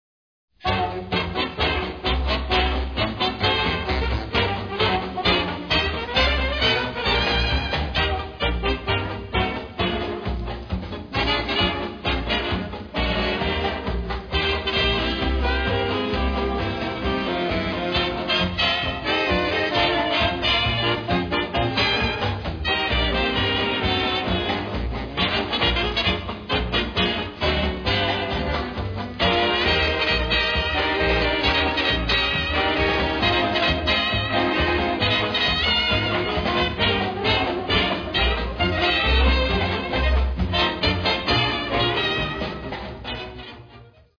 Signature tune